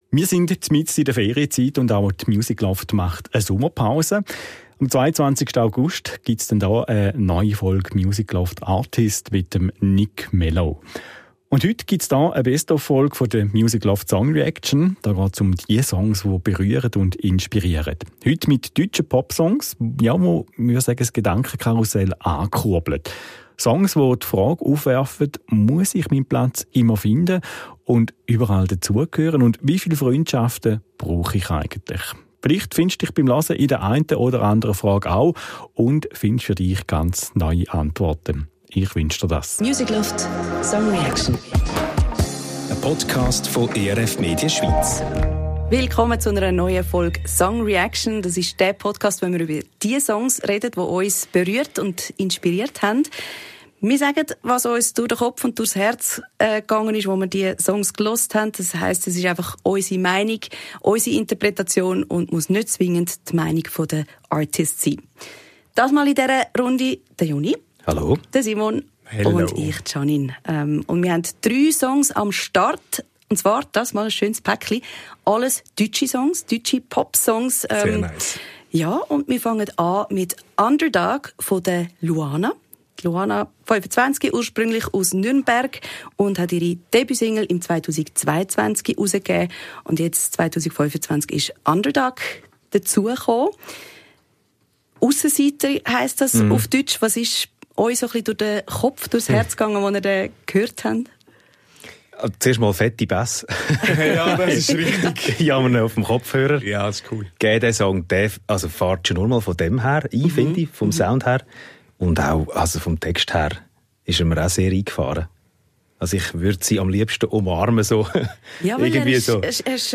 Zu Dritt sitzen wir im Studio und diskutieren über Songs, die uns berührt und inspiriert haben.